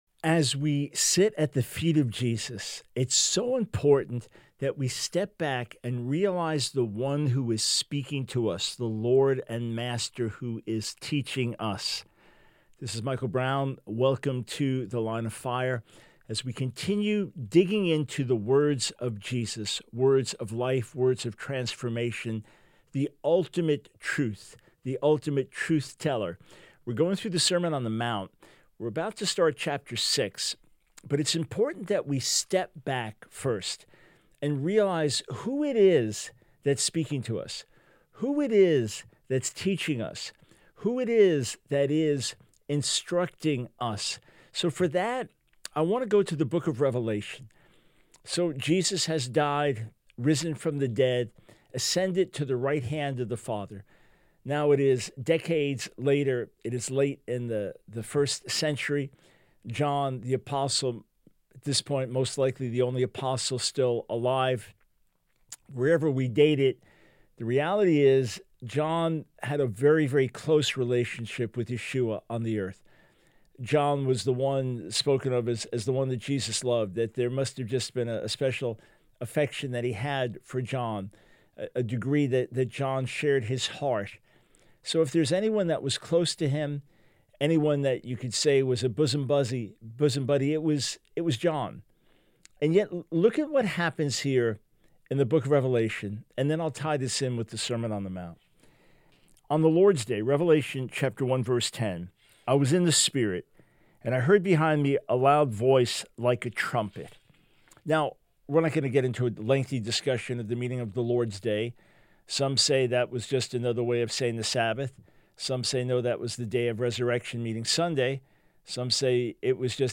Radio Broadcast from The Line of Fire